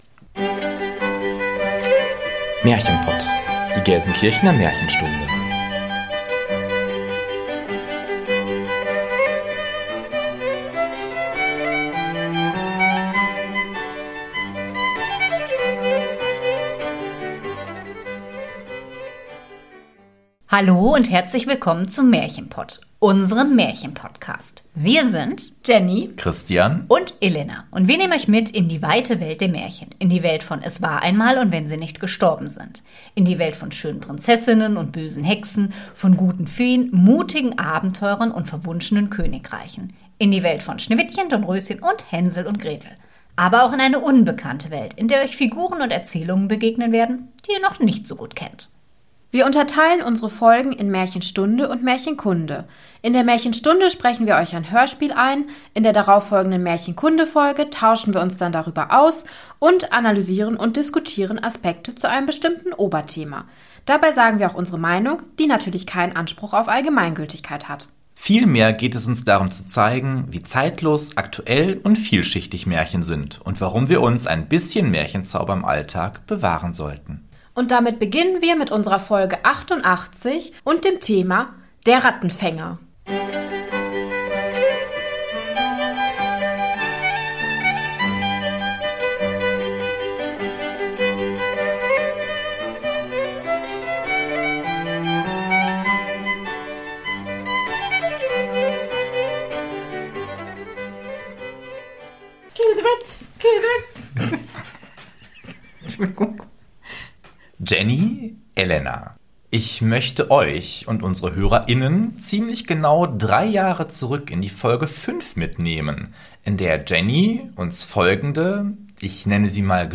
1 Kinder-Live-Hörspiel: Till Eulenspiegel vom ARD-Kinderhörspieltag 53:31